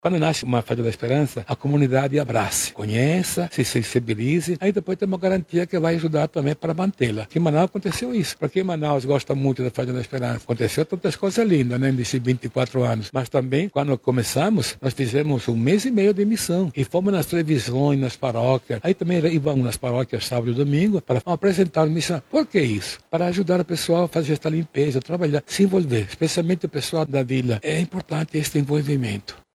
Dom Mário Pasqualotto, bispo emérito da Arquidiocese de Manaus, explica que, nesse primeiro momento, será trabalhado o envolvimento das comunidades para a continuidade e manutenção do projeto.
SONORA-1-FAZENDA-ESPERANCA-PARINTINS-.mp3